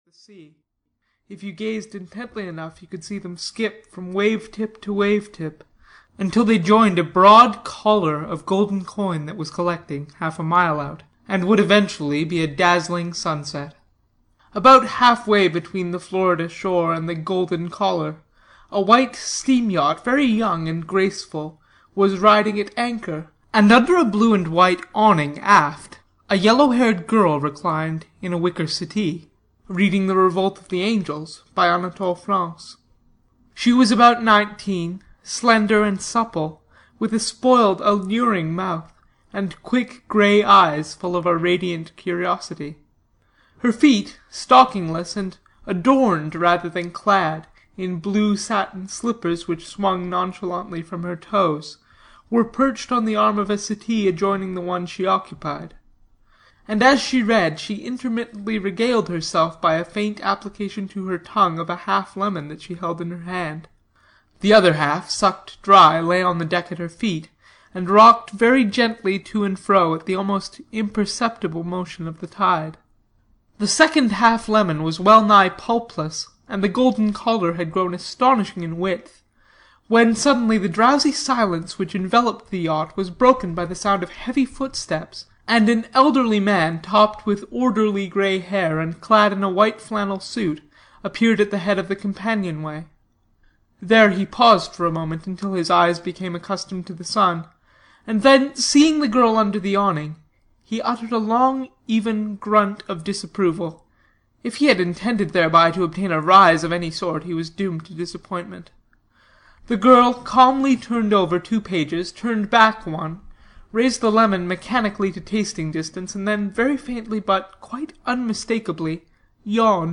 Flappers and Philosophers (EN) audiokniha
Ukázka z knihy